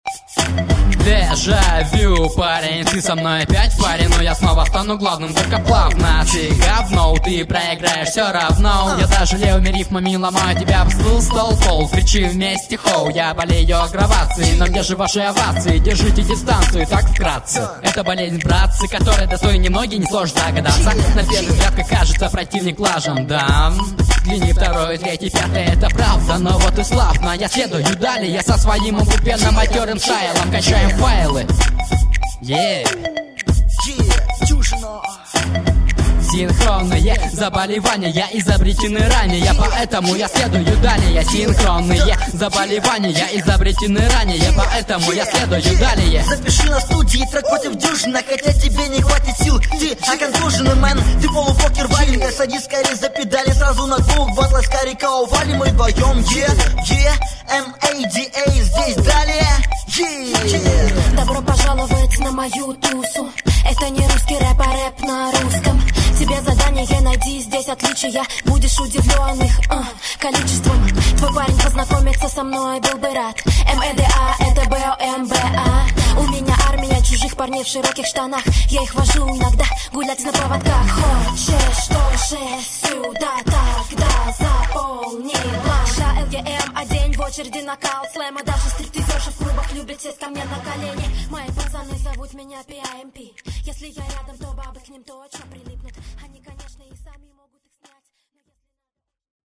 • Баттлы:, 2006-07 Хип-хоп
mp3,1555k] Рэп